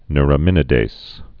(nrə-mĭnĭ-dās, -dāz, nyr-)